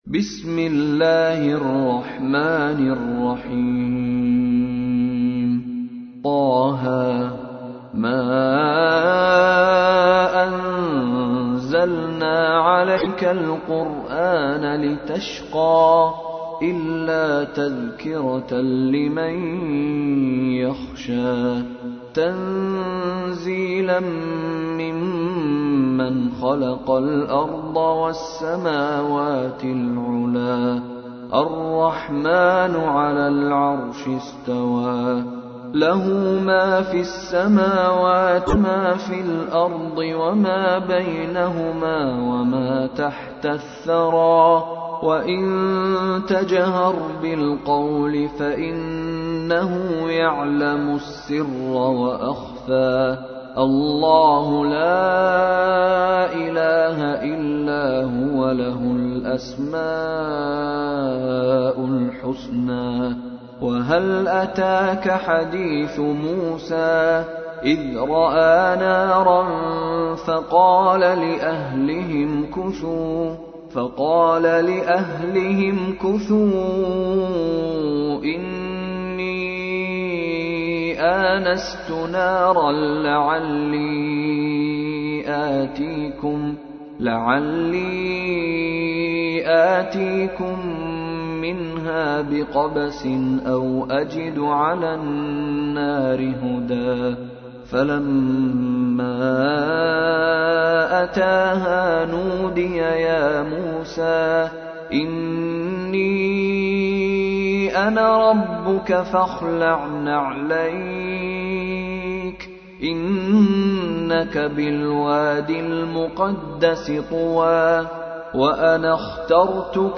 تحميل : 20. سورة طه / القارئ مشاري راشد العفاسي / القرآن الكريم / موقع يا حسين